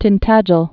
(tĭn-tăjəl)